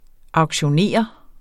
Udtale [ ɑwgɕoˈneˀʌ ]